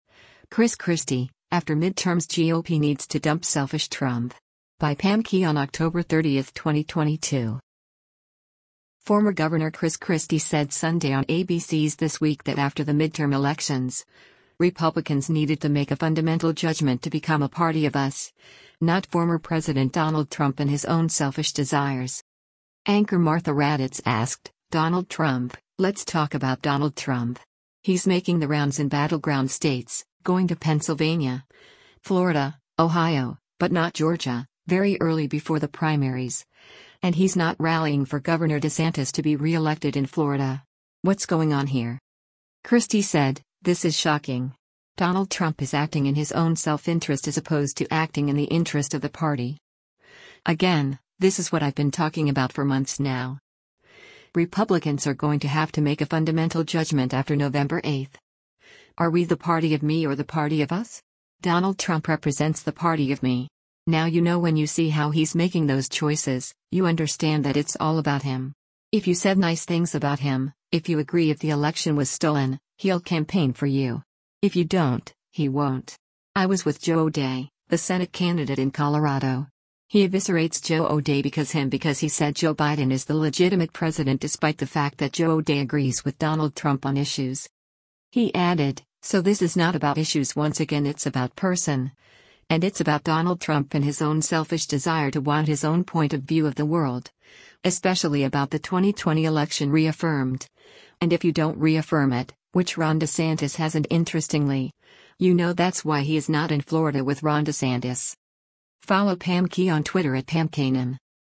Former Gov. Chris Christie said Sunday on ABC’s “This Week” that after the midterm elections, Republicans needed to make a “fundamental judgment” to become a Party of “us,” not former President Donald Trump and his own selfish desires.